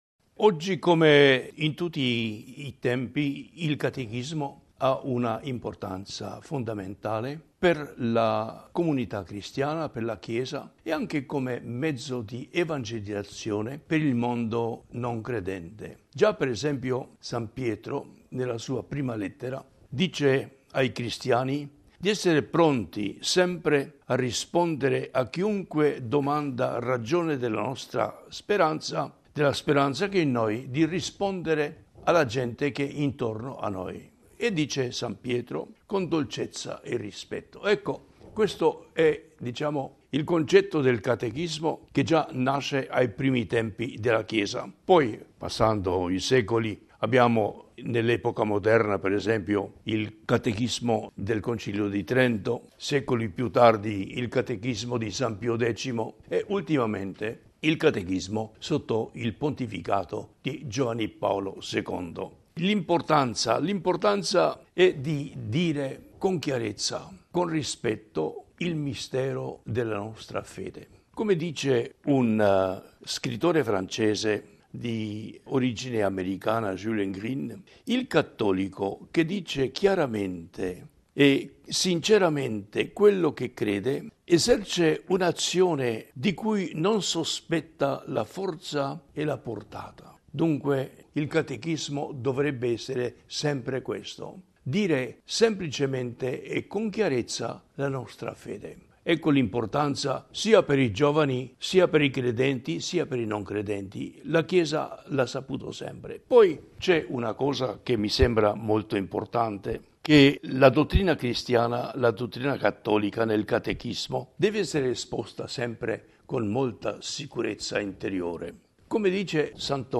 Ascolta l’intervista integrale al cardinale Rodé